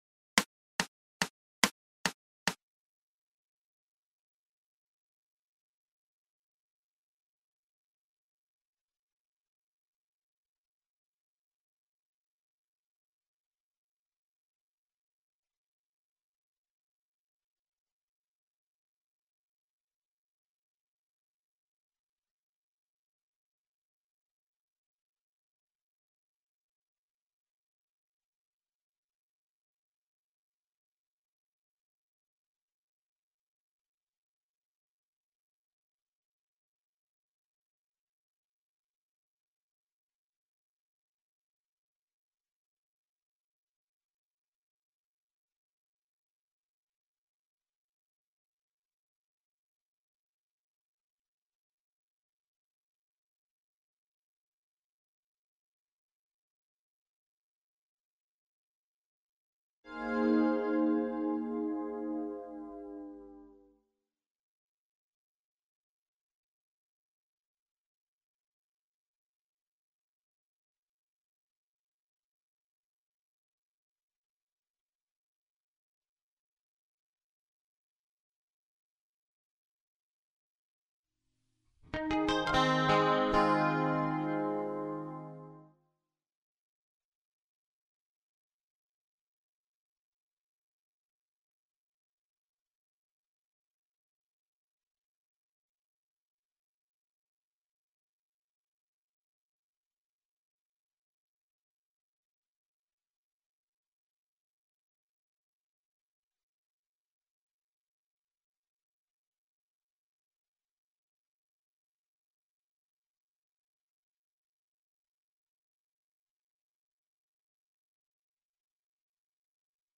GUITARRA ELÉCTRICA RÍTMICA - ARPEGIO 2 (Descargar)